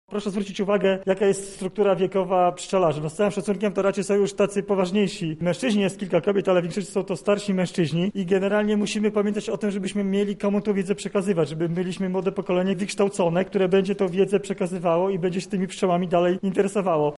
-mówi Sebastian Trojak członek Zarządu Województwa Lubelskiego.